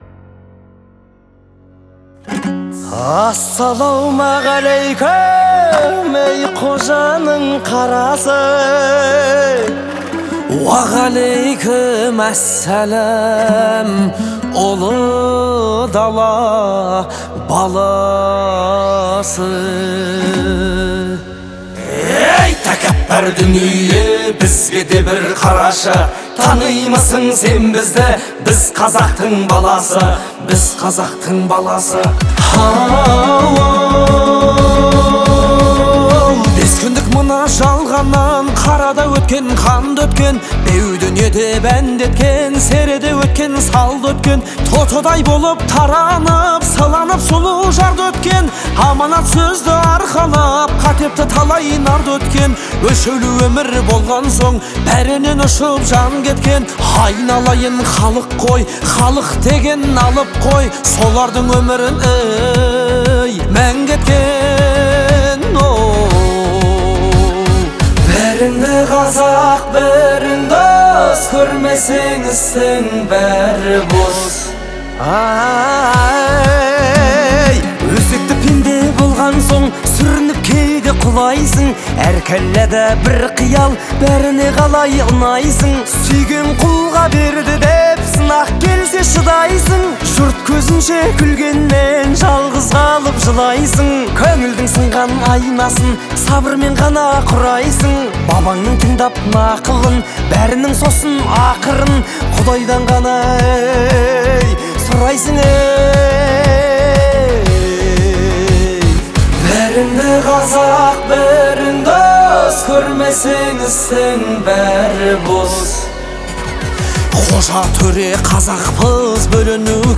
это яркий пример казахского поп-музыки